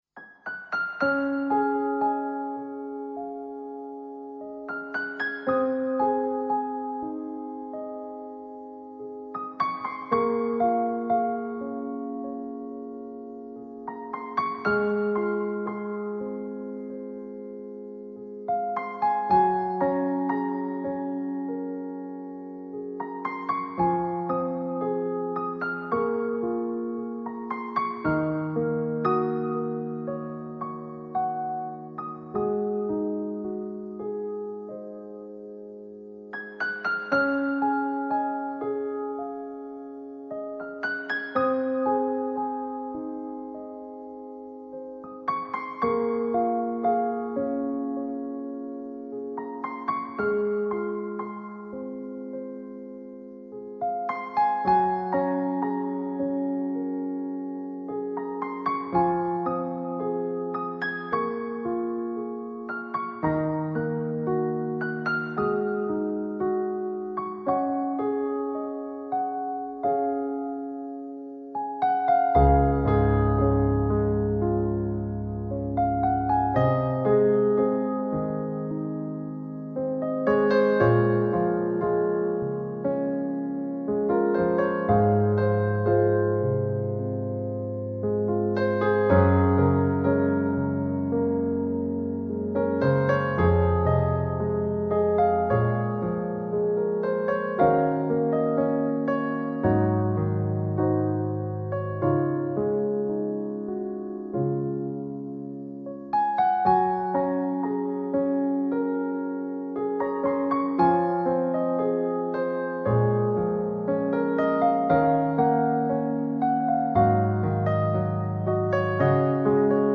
钢琴版